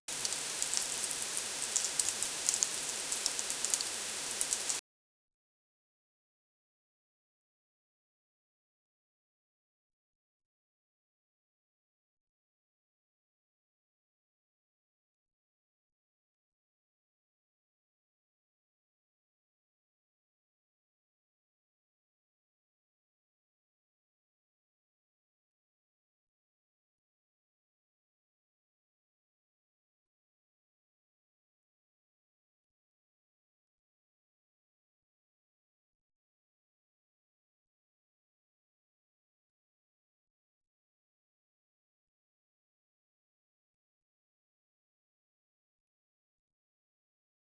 Stable Audio - Raindrops, output.wav